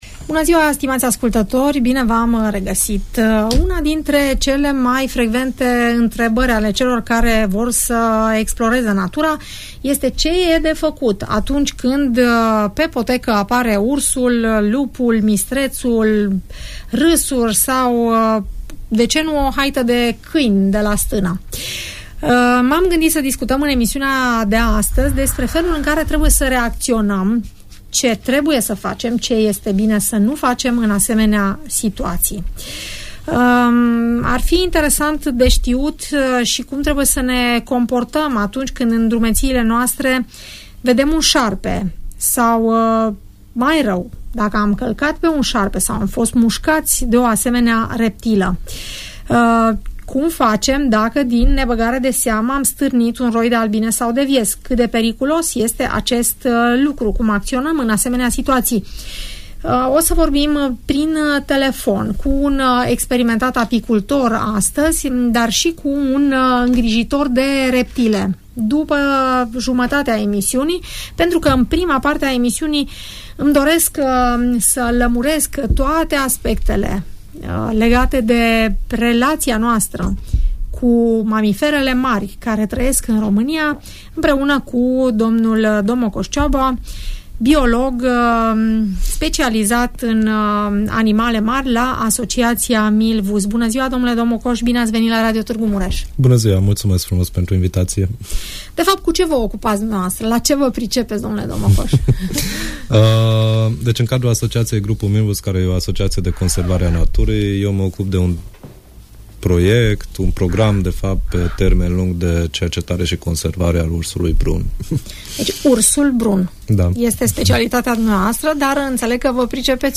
Invitat în studio